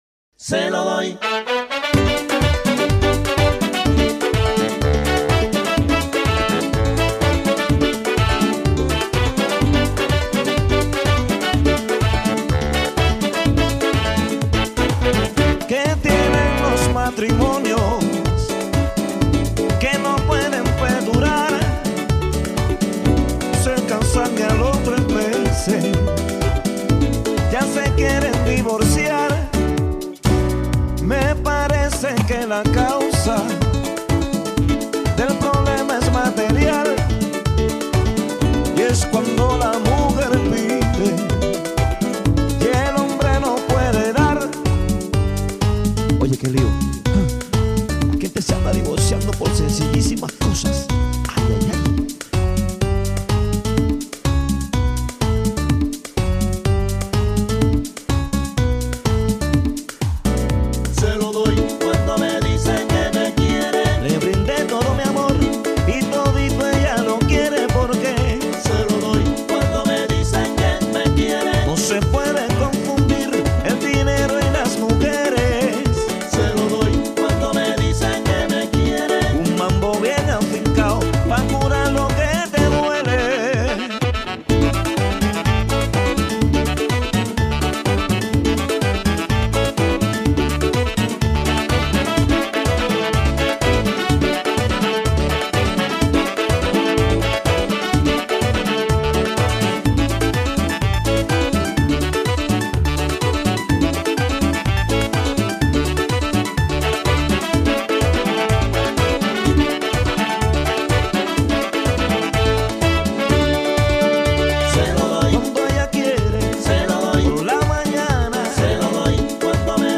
• Latin/Salsa/Reggae